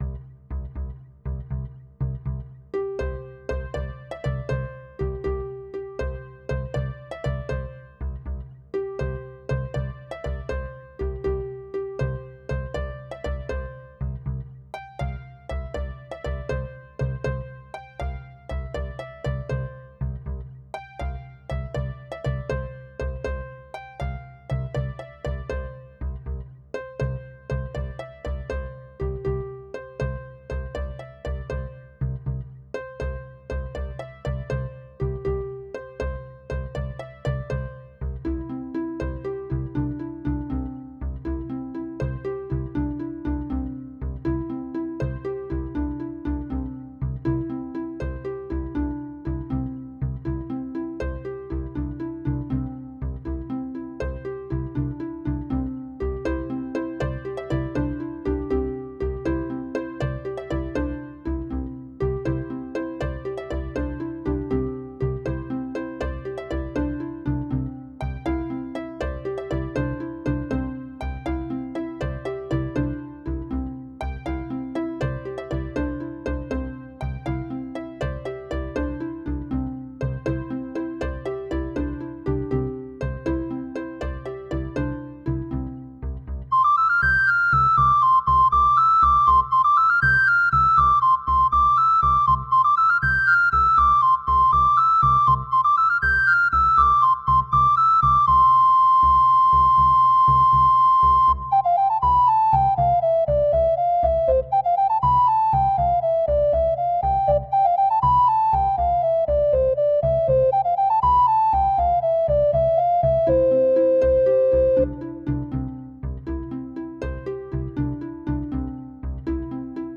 melodía
sintonía